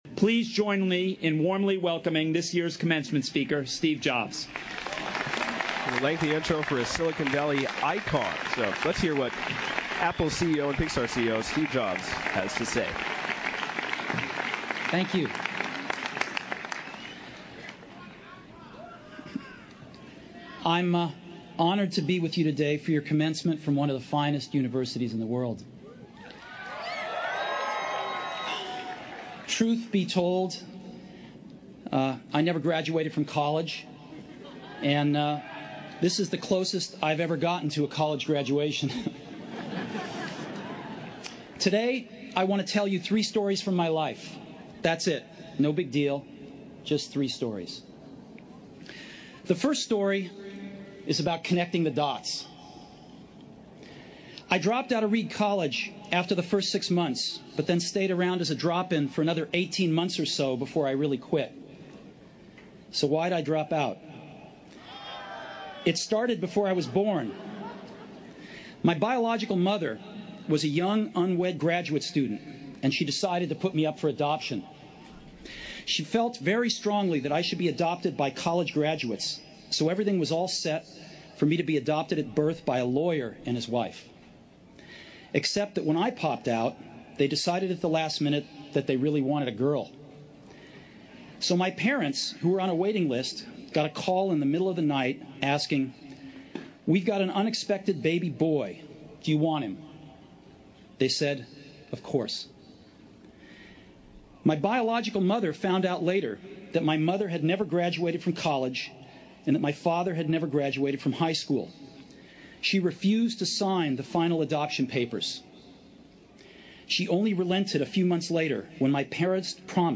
Steve-Jobs-Stanford-Cut.mp3